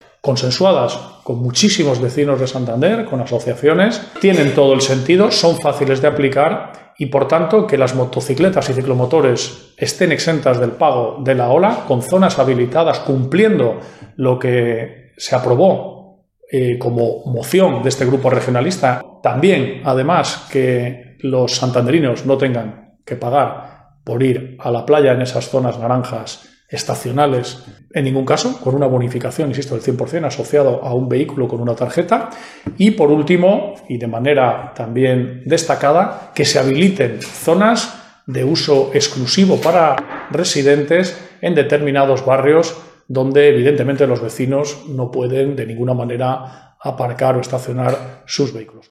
El secretario general y portavoz municipal del PRC, Felipe Piña, ha ofrecido este jueves una rueda de prensa en la que ha explicado que todas ellas son medidas "factibles" y consensuadas con las asociaciones vecinales y colectivos y ha destacado la creación de una zona verde, "con espacios exclusivos y tiempo ilimitado", para los residentes de ciertos barrios "tensionados".